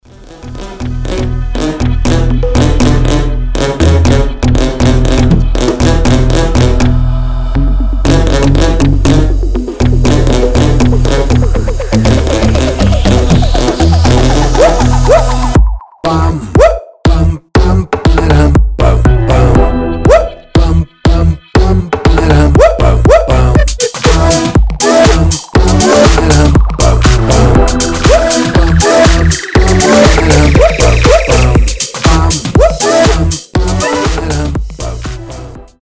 • Качество: 320, Stereo
громкие
Club House
мощные басы
future house
рождественские
Neoclassical